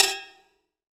ETIMBALE H1O.wav